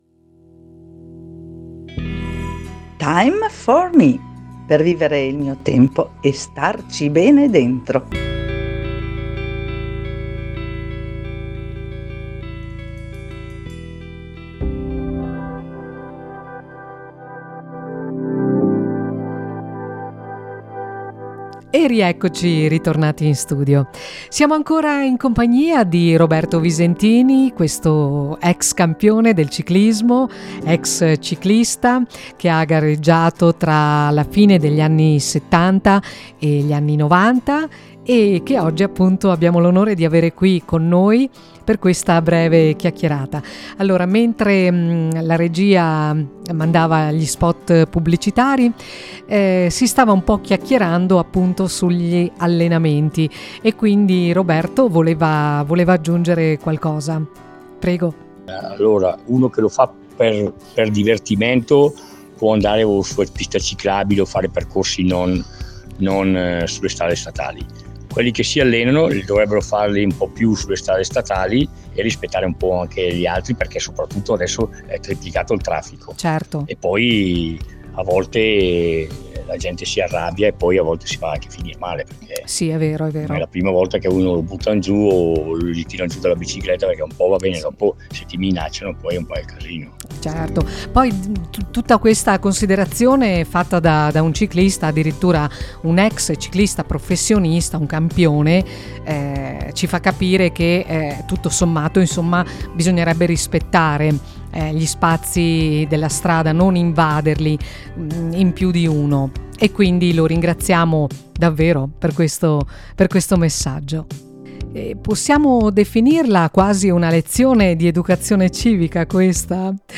OSPITE: Roberto Visentini